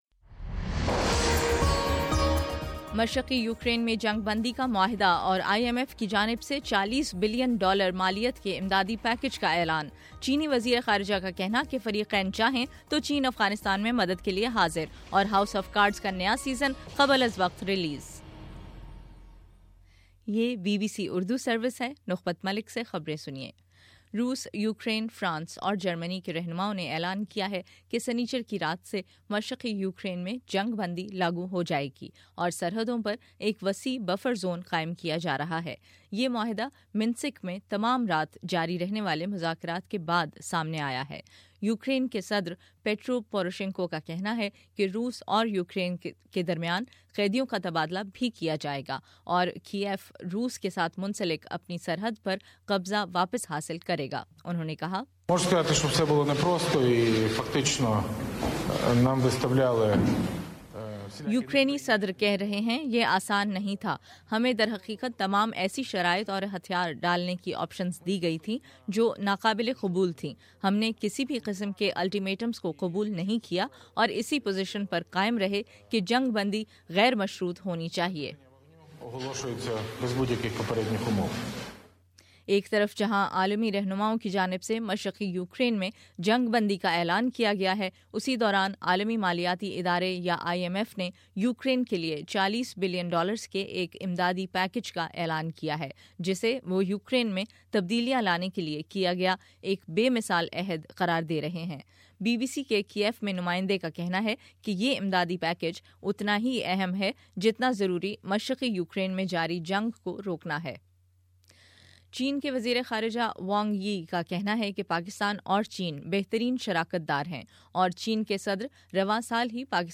فروری12: شام سات بجے کا نیوز بُلیٹن